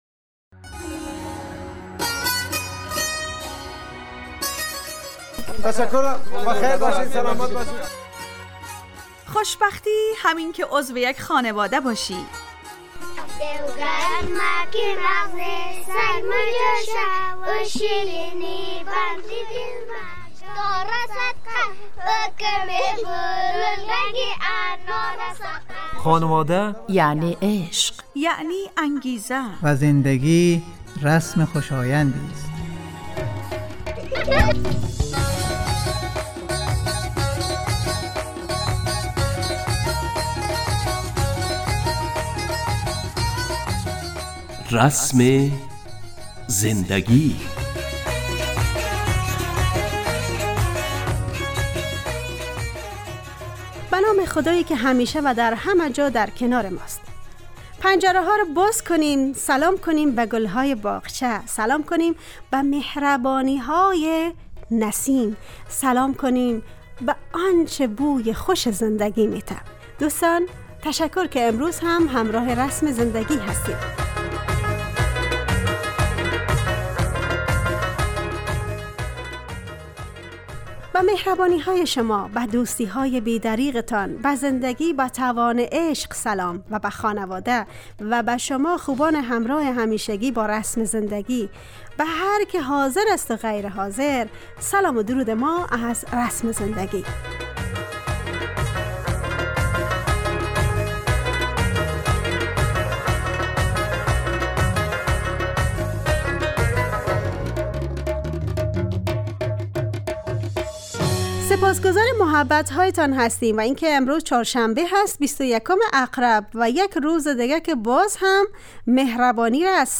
برنامه خانواده رادیو دری